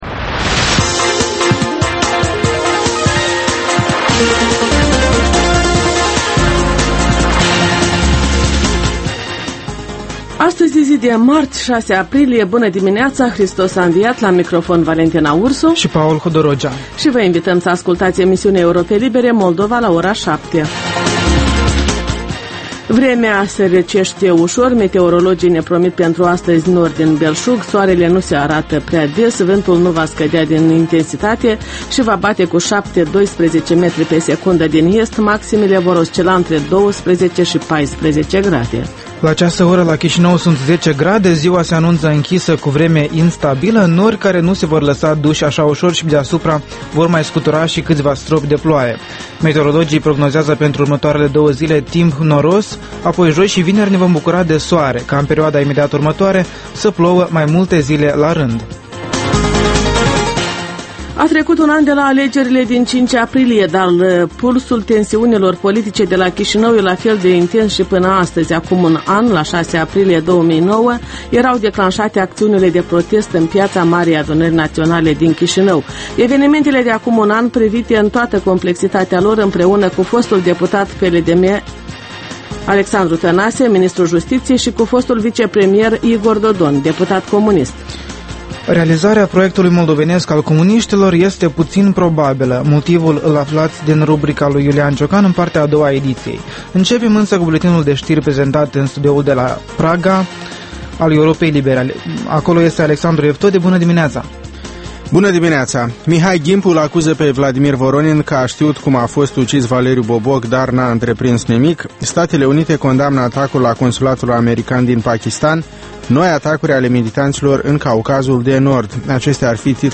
Ştiri, interviuri, analize.